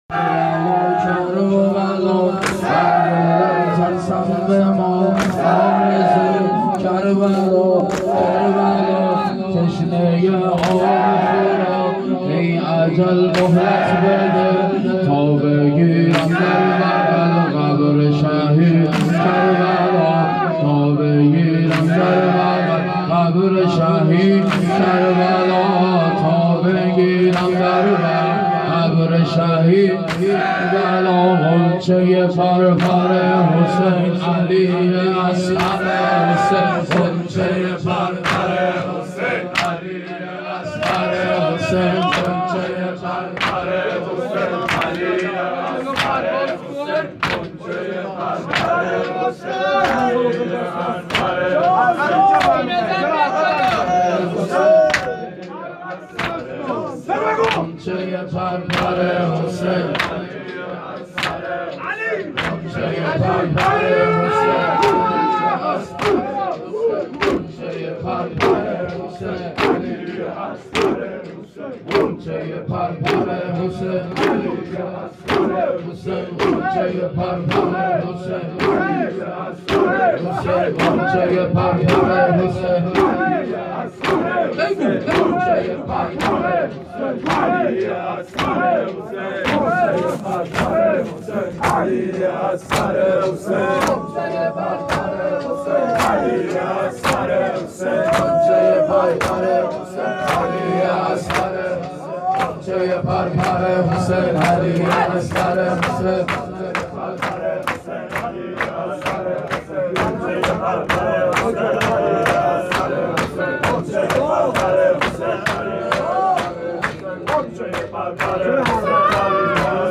شب حضرت علی اصغر علیه السلام - 3 آبان 95
3 0 شور